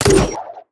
gr_launch02.wav